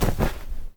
glider_close.ogg